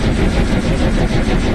fan_running_01.ogg